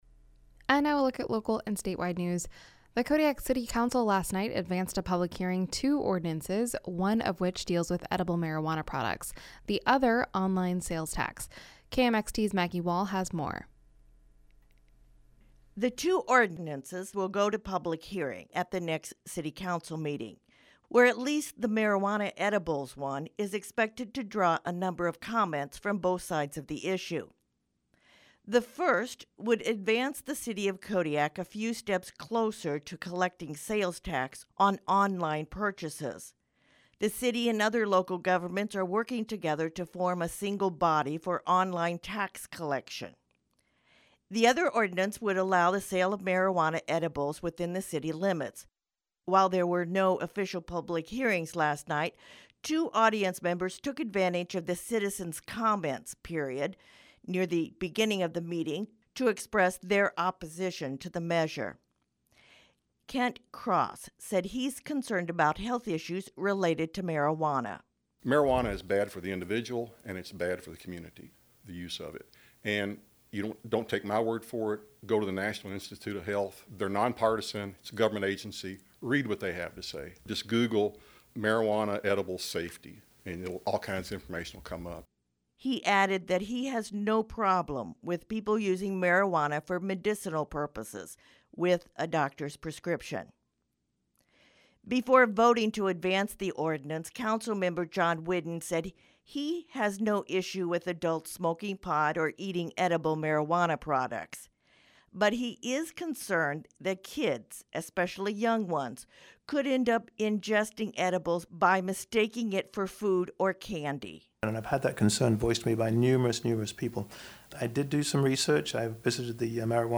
Newscast — Friday, Feb. 28, 2020